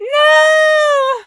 rosa_death_03.ogg